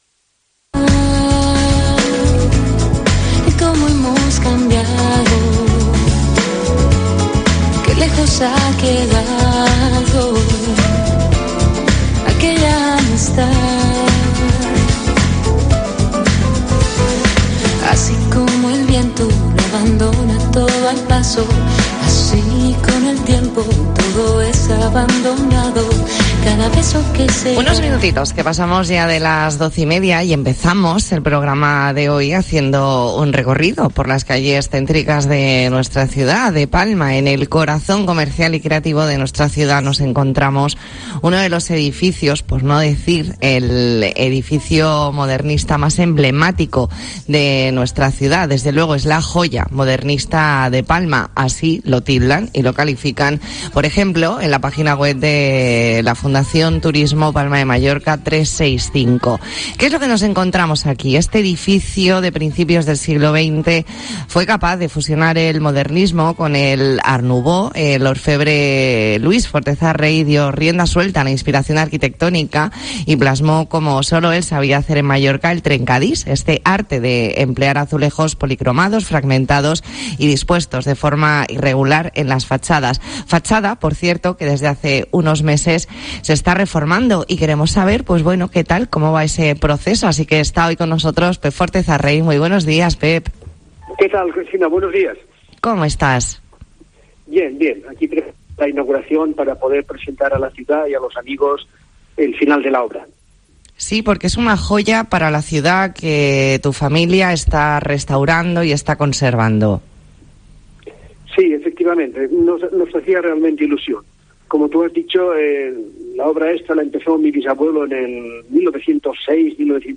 Entrevista en La Mañana en COPE Más Mallorca, jueves 17 de febrero de 2022.